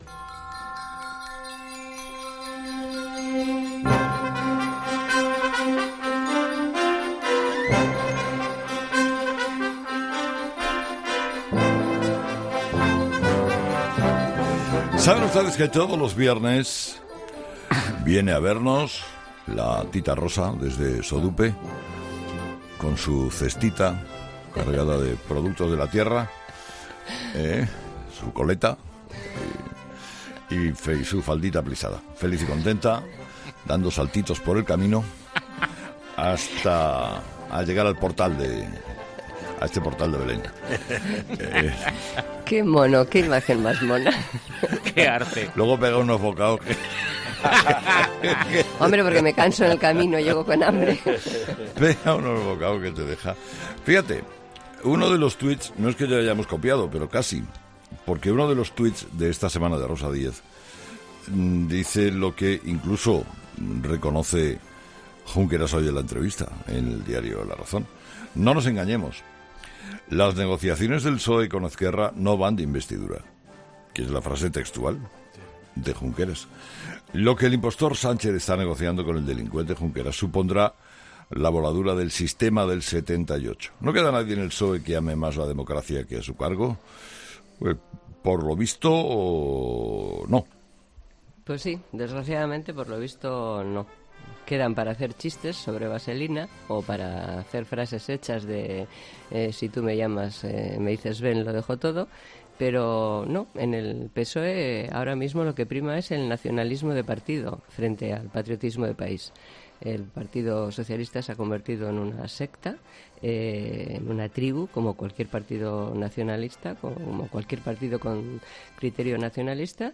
El PSOE se ha convertido en una secta y no aman más al país que a su cargo", ha dicho la exdiputada en su sección semanal en "Herrera en COPE".